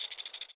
rifle_shake.wav